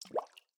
drip_water_cauldron4.ogg